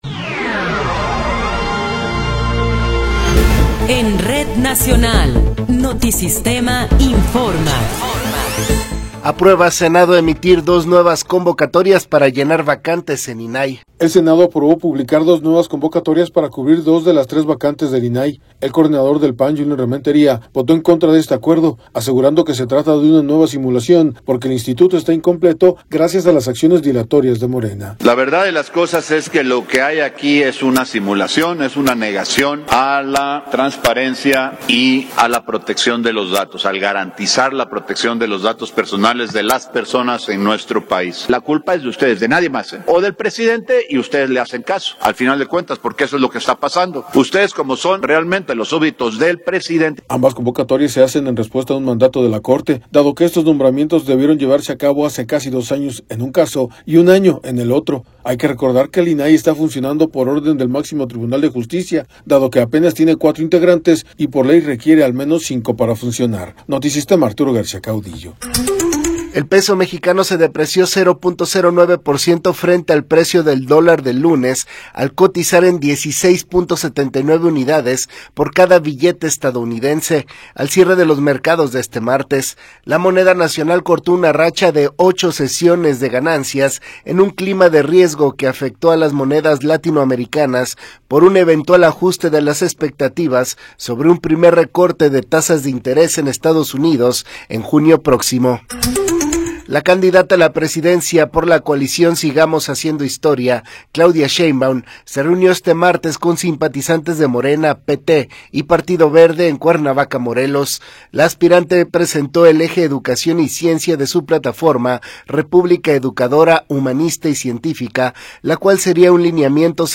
Noticiero 18 hrs. – 12 de Marzo de 2024